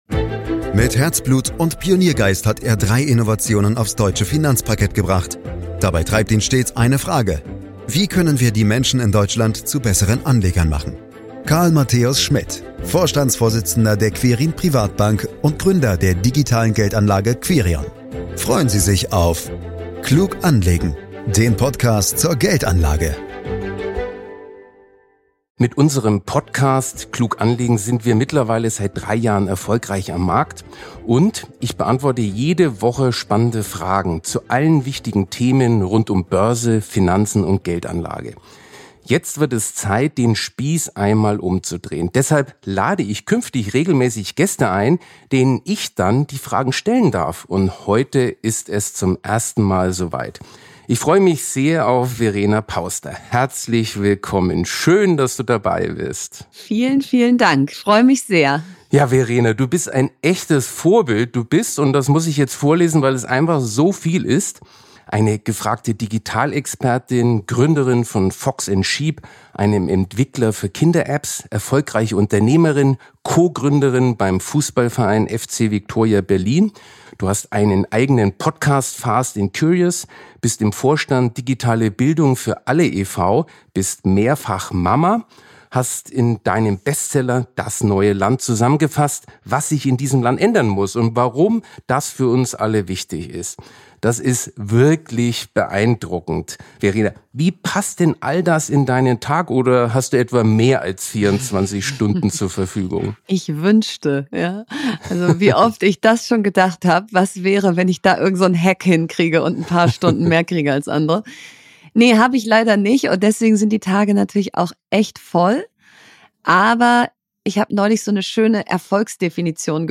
Unser erster Gast ist Verena Pausder.